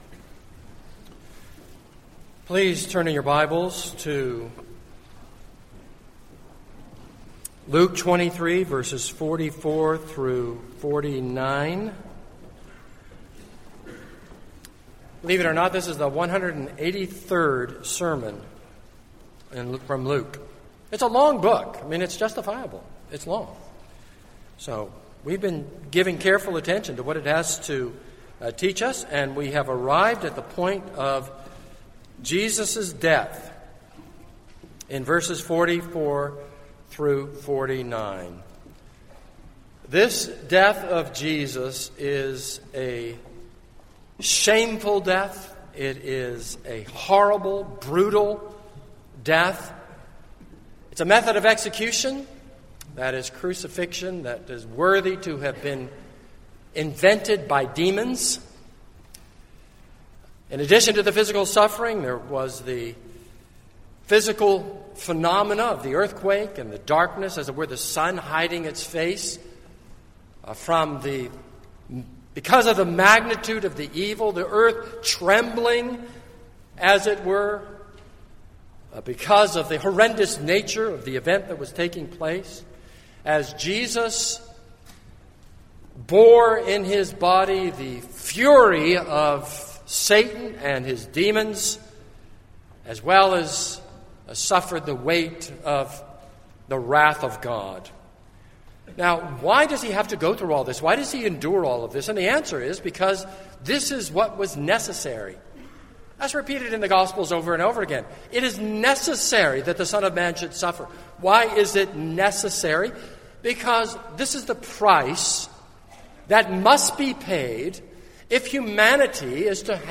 This is a sermon on Luke 23:44-49.